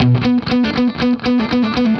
AM_HeroGuitar_120-B01.wav